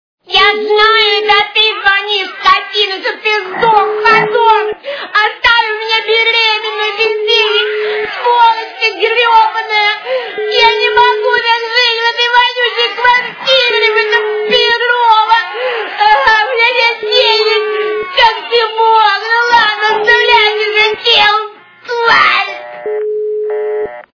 » Звуки » другие » Автоответчик-истеричка - Я знаю это ты звонишь, чтоб ты сдох, подонок, оставил меня беременную без денег... , ну ладно, оставляй, че хотел, тварь....